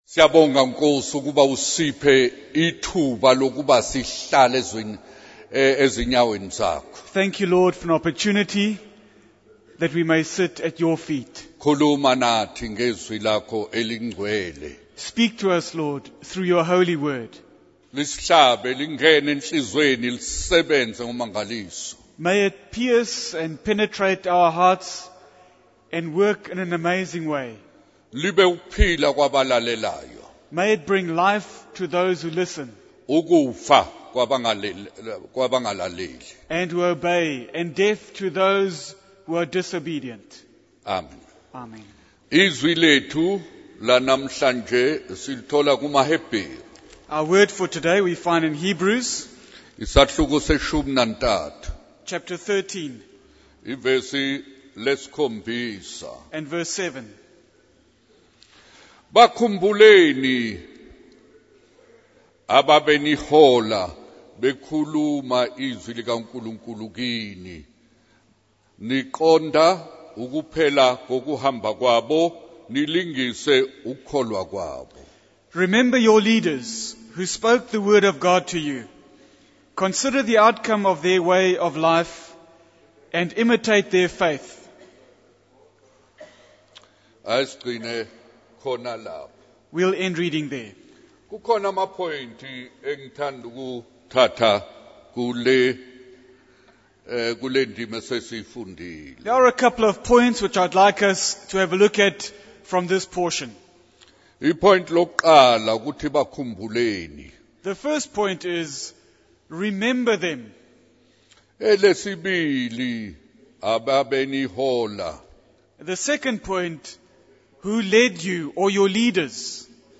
In this sermon, the speaker emphasizes the importance of remembering and imitating leaders who speak the word of God. He warns against the danger of seeking teachers who only say what people want to hear, rather than sound doctrine.